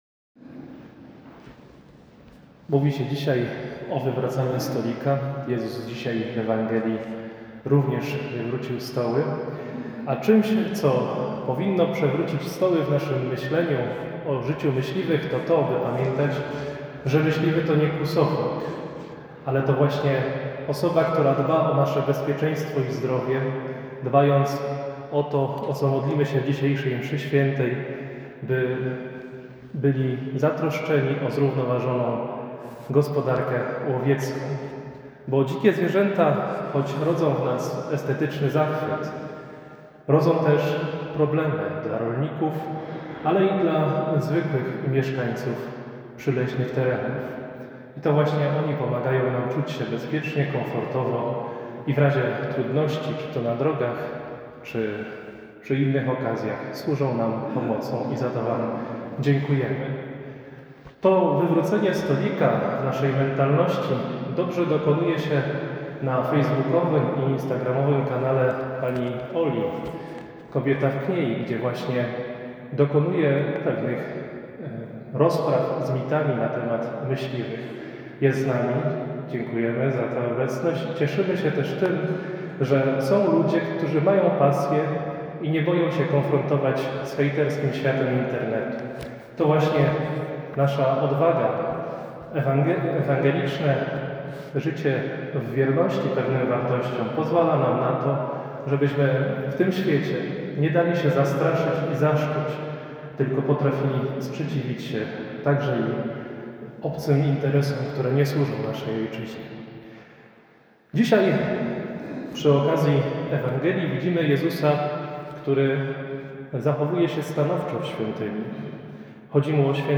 Poniżej zamieszczamy zapis homilii skierowanej do myśliwych z tej Mszy Świętej (09.11.2025 r., godz. 7:00).